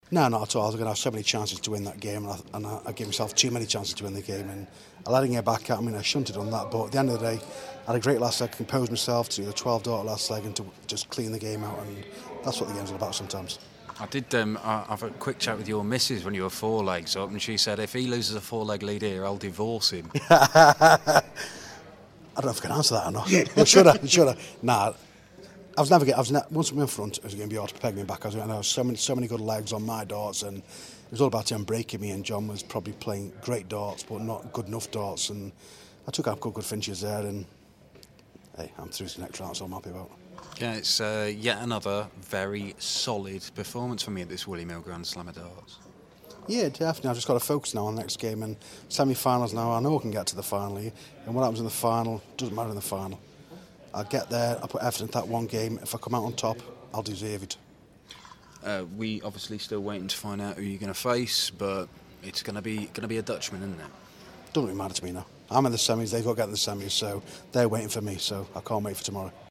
William Hill GSOD - Hamilton Interview (QF)